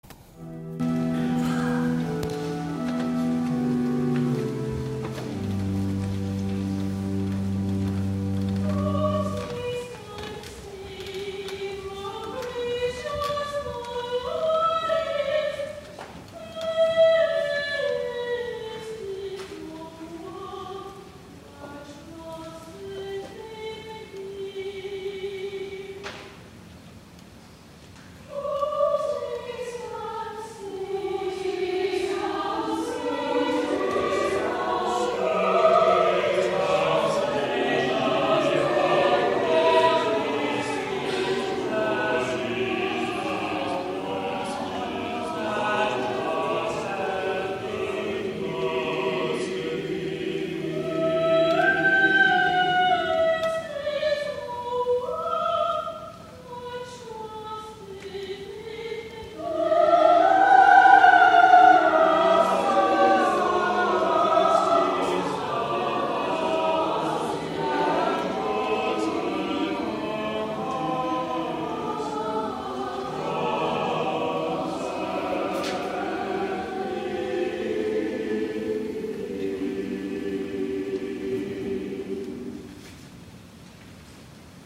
SECOND SUNDAY IN LENT
*THE CHORAL RESPONSE